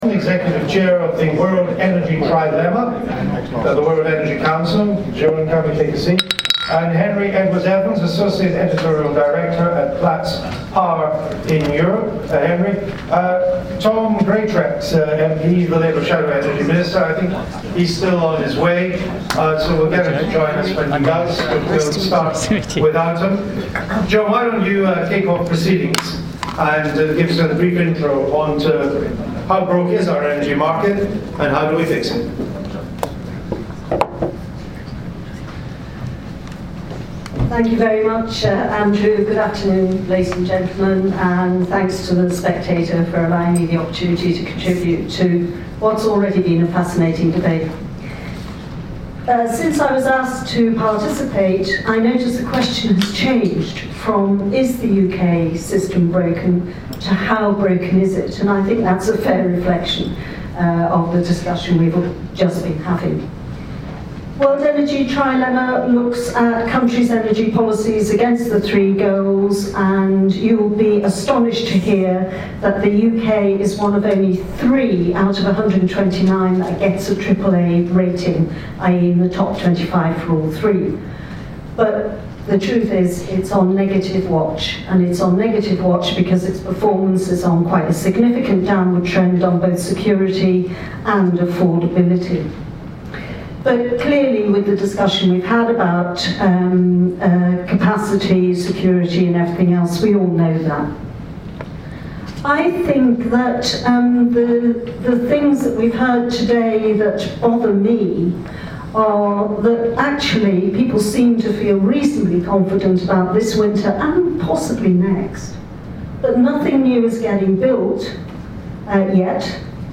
Panel: How broke is the UK energy market and how should it be fixed? Tom Greatrex MP, Labour shadow energy minister